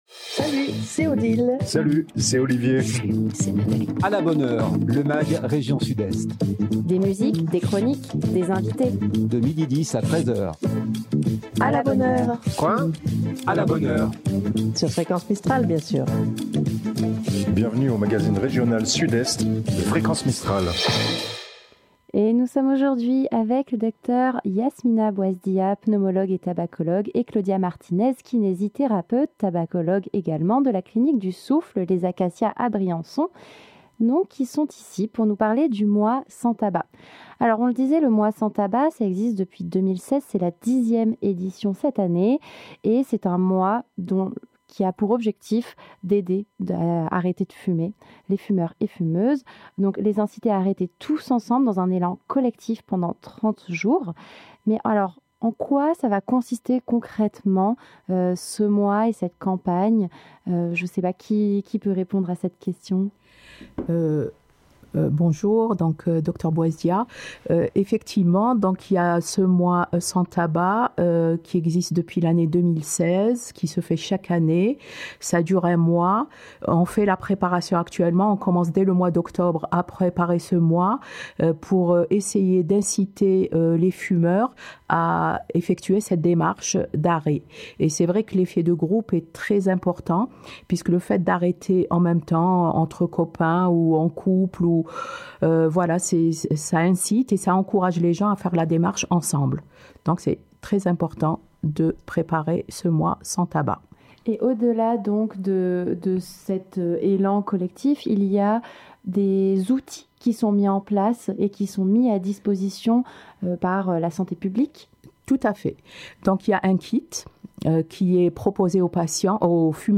" Bienvenue dans le magazine région Sud-Est de Fréquence Mistral !